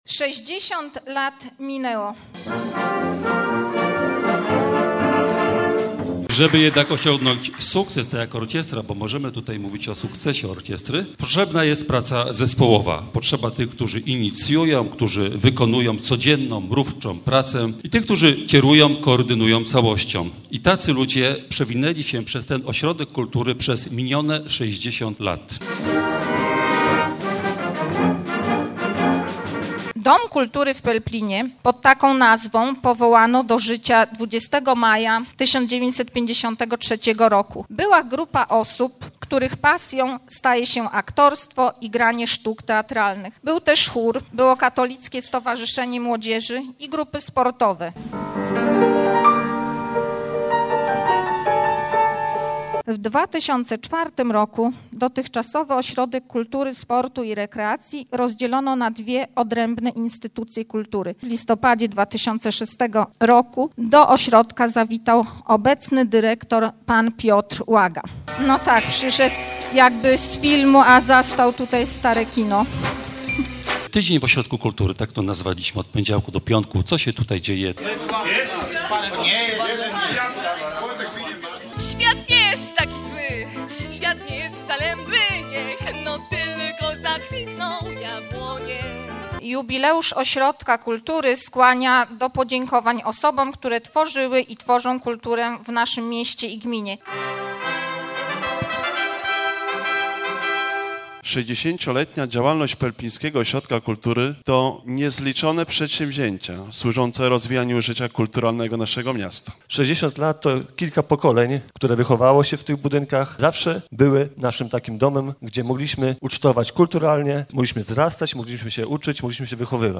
29 maja 2013 roku, Ośrodek Kultury w Pelplinie świętował 60.lecie działalności.
Relacja Radia Głos (3,289 kB)